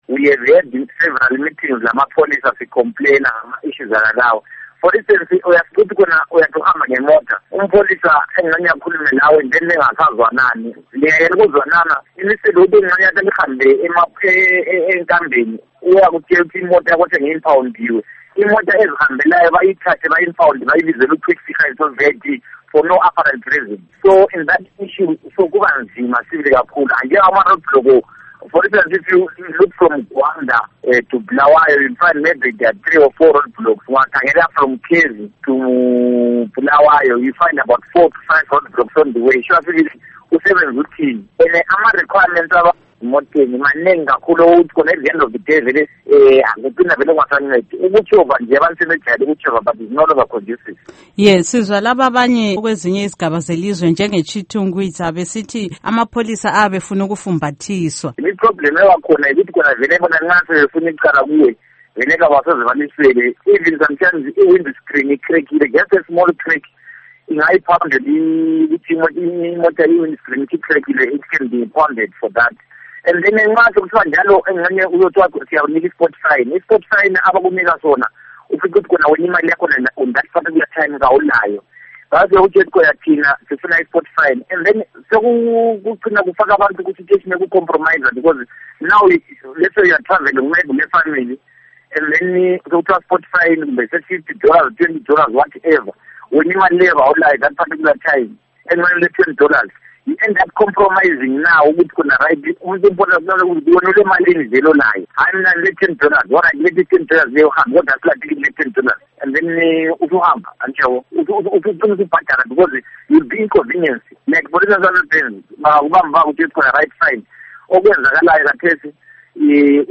IIngxoxo